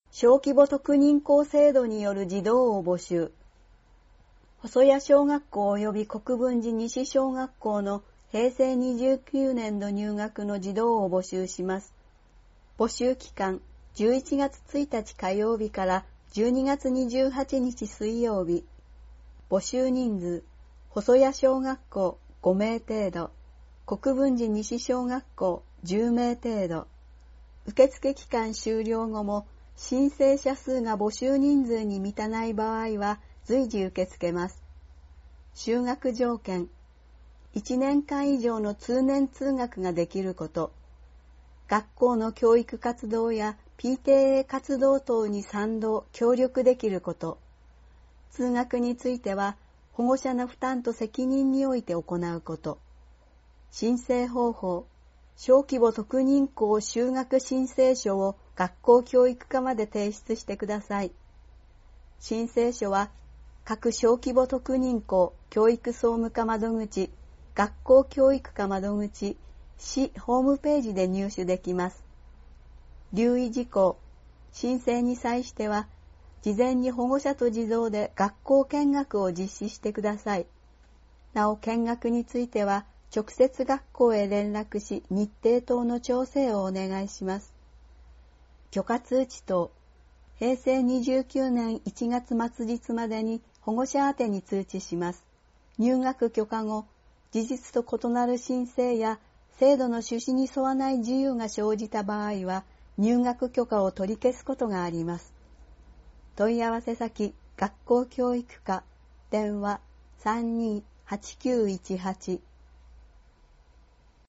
音声ファイルで読み上げられるページ番号はデイジー版（←無償配布を行っています。くわしくはこちらをクリックしてください。）用となっております。